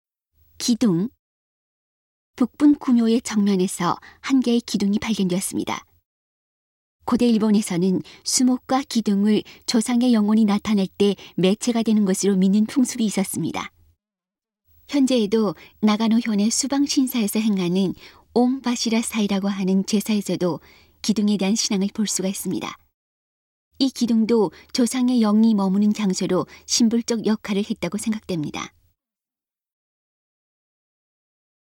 이 기둥도 조상의 영혼이 머무는 장소로 상징적인 역할을 했다고 생각됩니다. 음성 가이드 이전 페이지 다음 페이지 휴대전화 가이드 처음으로 (C)YOSHINOGARI HISTORICAL PARK